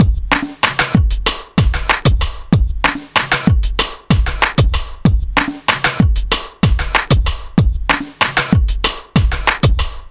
描述：快乐
Tag: 95 bpm Hip Hop Loops Drum Loops 1.70 MB wav Key : Unknown